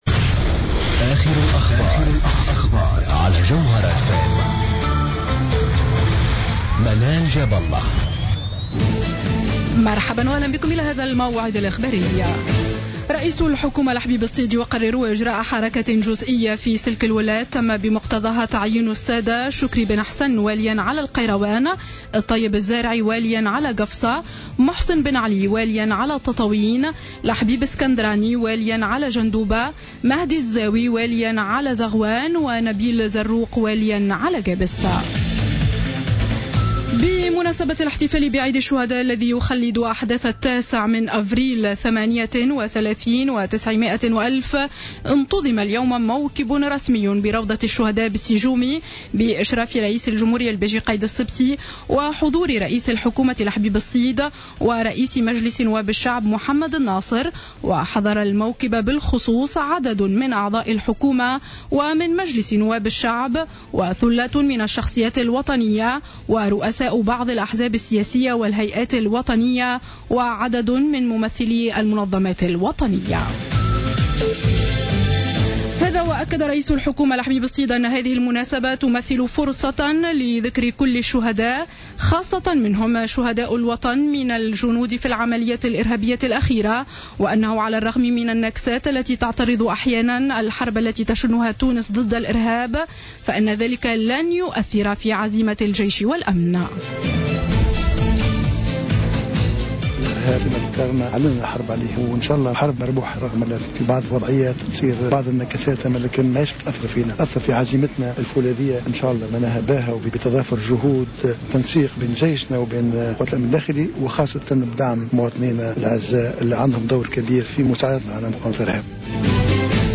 نشرة أخبار السابعة مساء ليوم الخميس 9 أفريل 2015